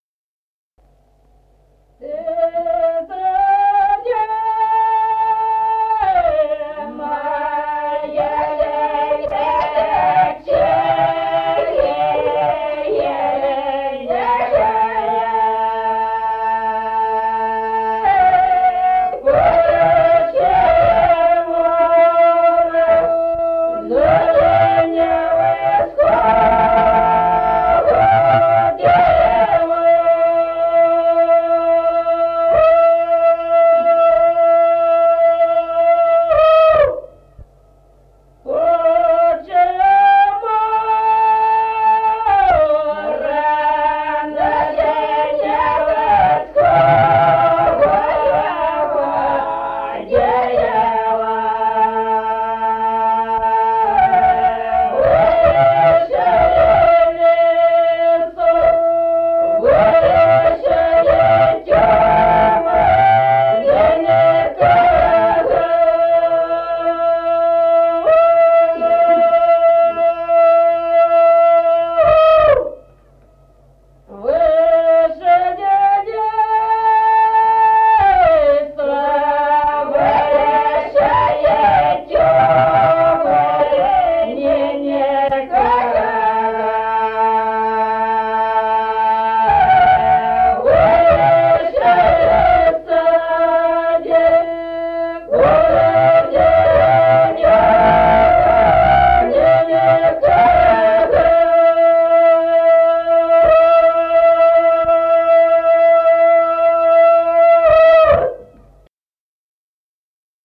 Народные песни Стародубского района «Ты заря», масленичная.
с. Остроглядово